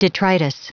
Prononciation du mot detritus en anglais (fichier audio)
Prononciation du mot : detritus